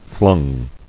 [flung]